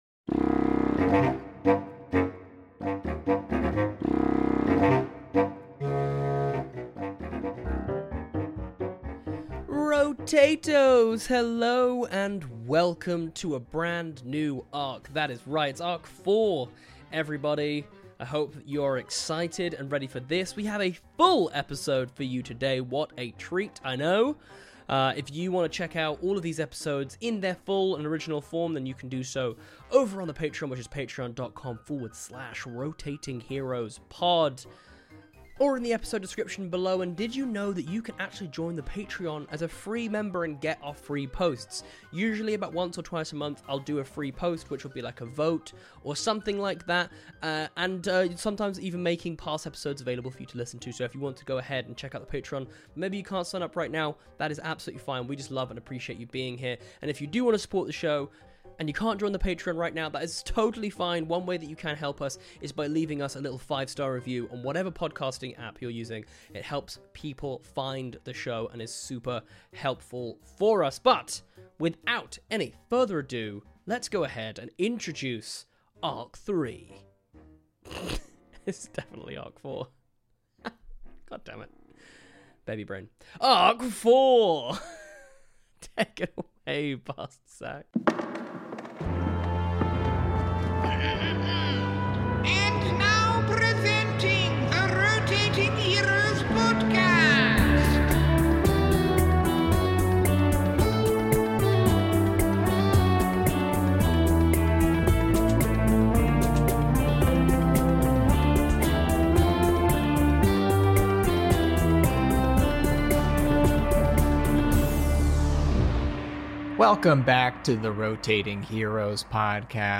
Zac Oyama was your Dungeon Master (Dimension 20, College Humour, Dropout, Adam Ruins Everything, Game Changer, and Um, Actually)